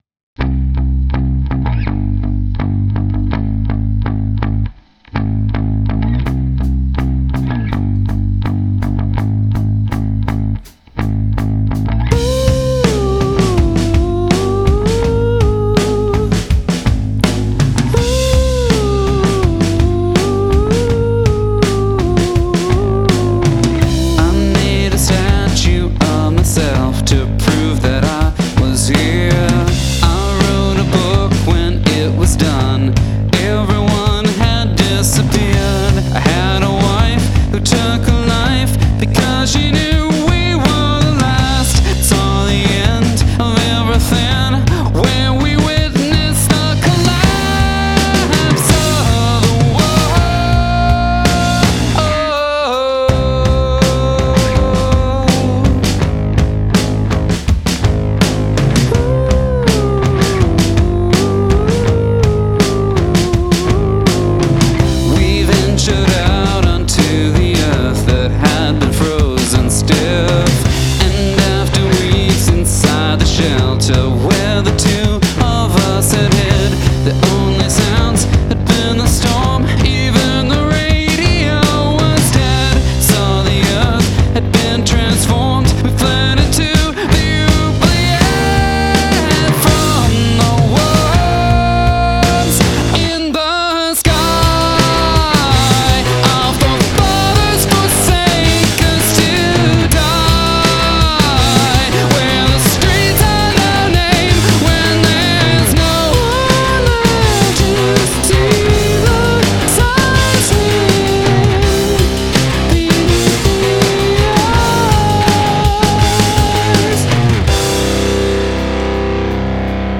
I love the writing and the drumming.